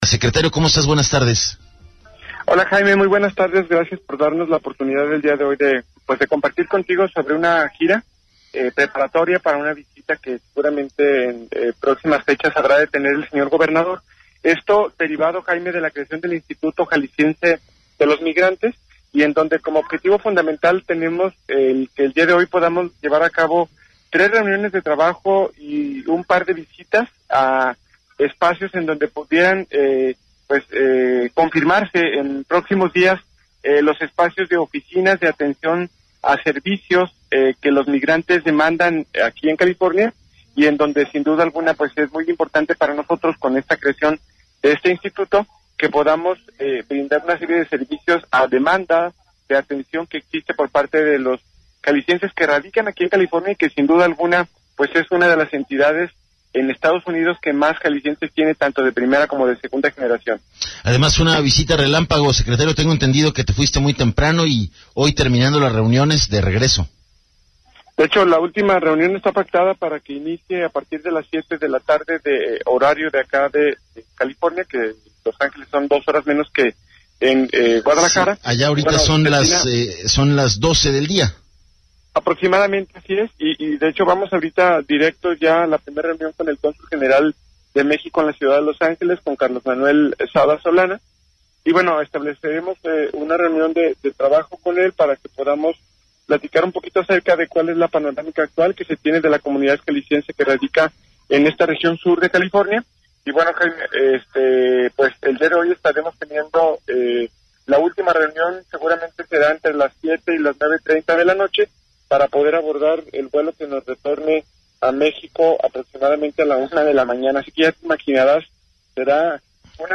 ENTREVISTA 100316